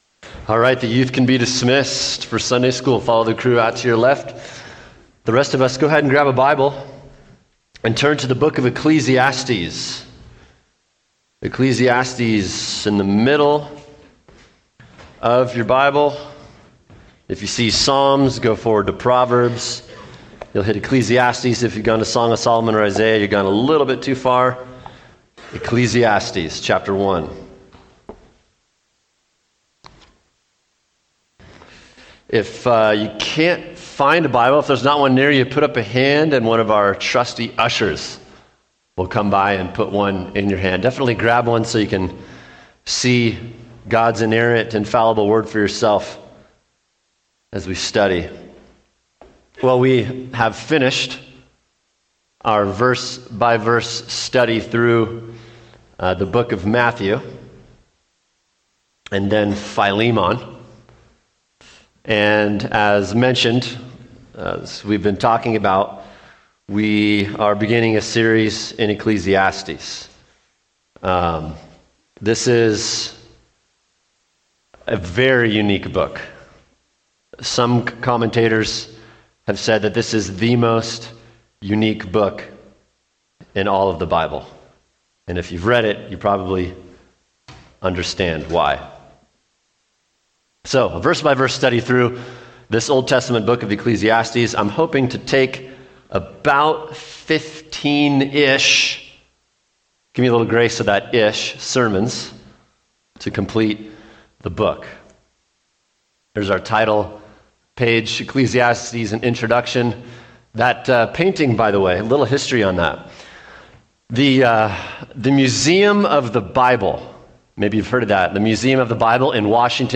Introduction download sermon